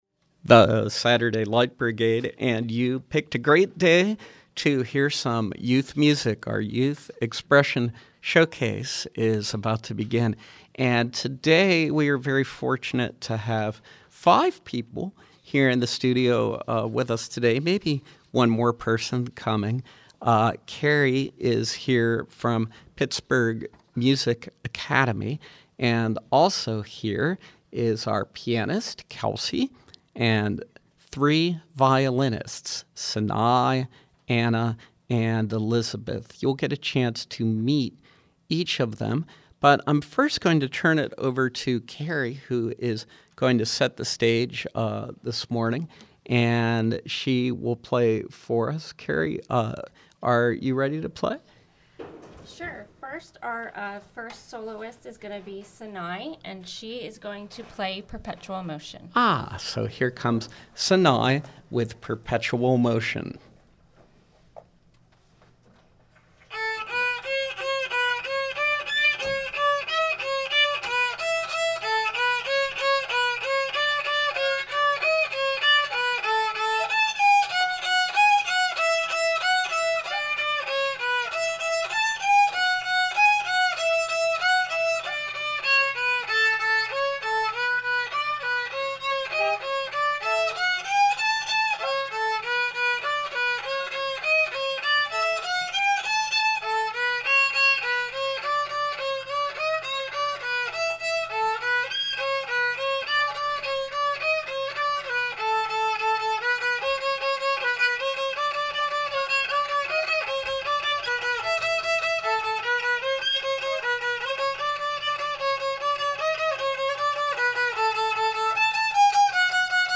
A Pittsburgh Music Academy ensemble featuring students
piano
violins